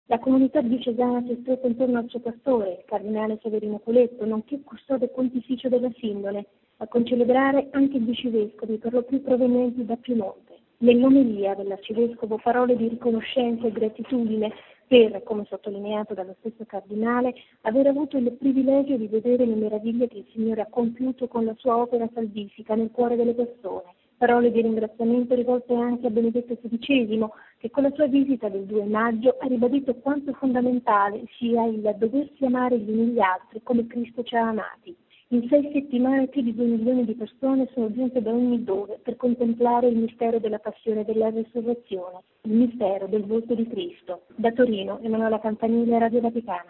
Il servizio della nostra inviata a Torino